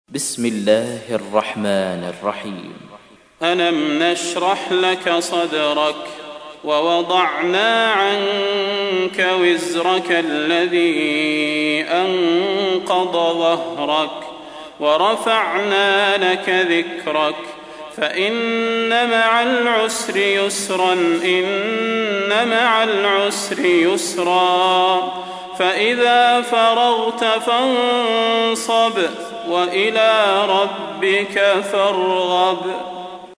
تحميل : 94. سورة الشرح / القارئ صلاح البدير / القرآن الكريم / موقع يا حسين